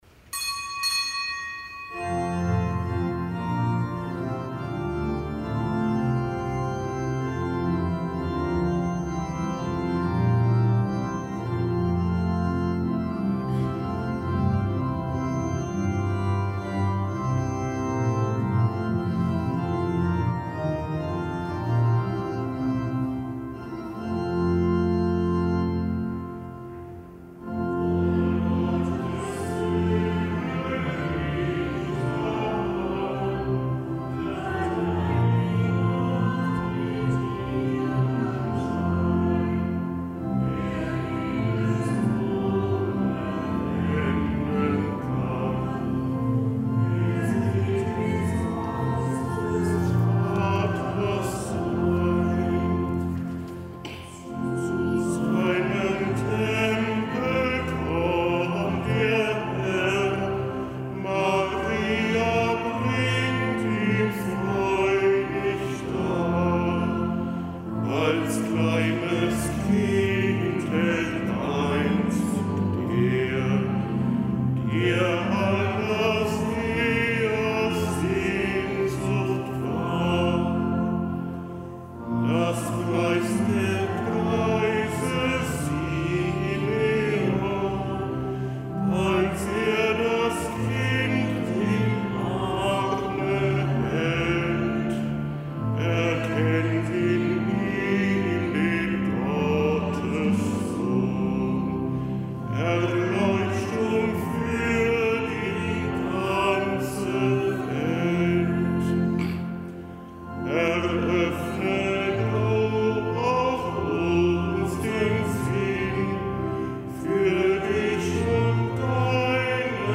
Kapitelsmesse am Fest Darstellung des Herrn
Kapitelsmesse aus dem Kölner Dom am Fest Darstellung des Herrn.